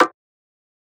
SB - Boomin (Perc).wav